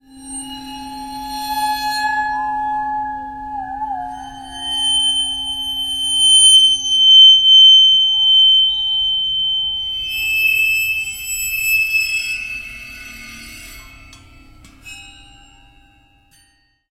描述：弓形金属打击乐器充满水，单声道未处理的家庭录音